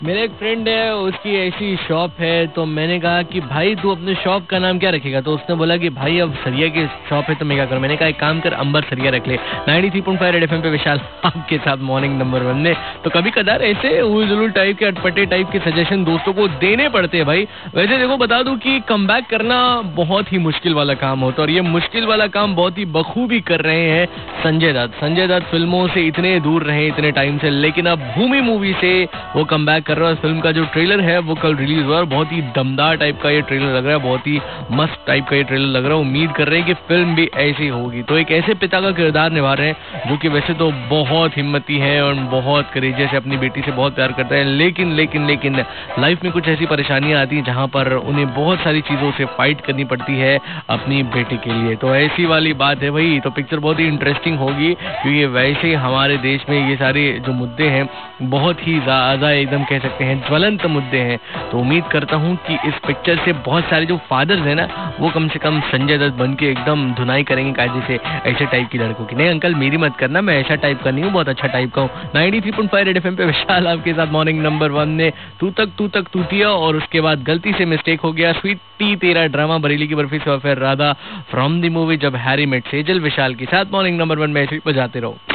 Rj About Bhoomi Movie Traliour